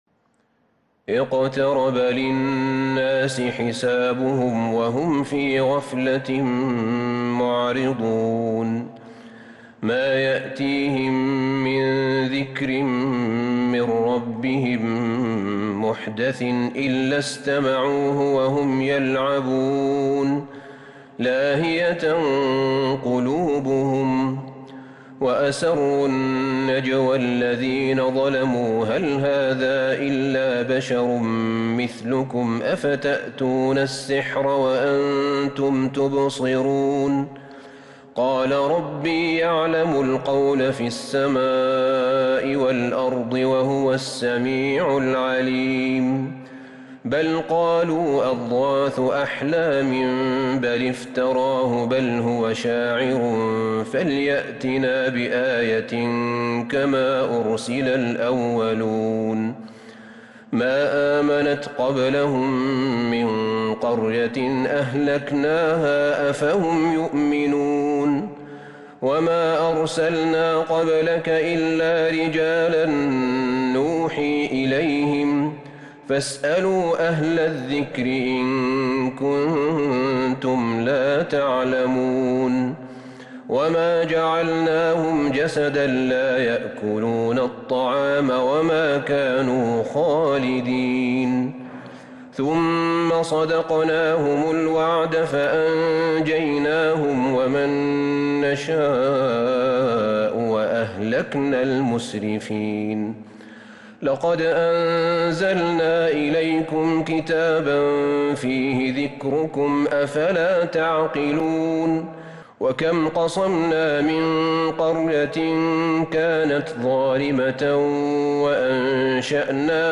سورة الأنبياء كاملة من تراويح الحرم النبوي 1442هـ > مصحف تراويح الحرم النبوي عام 1442هـ > المصحف - تلاوات الحرمين